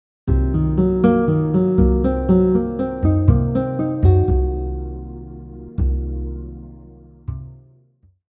This first example uses an ascending four note pattern using only the C minor 7 b5 chord tones: C, Eb, G, and Bb.
half-diminished-arpeggio-example-1.mp3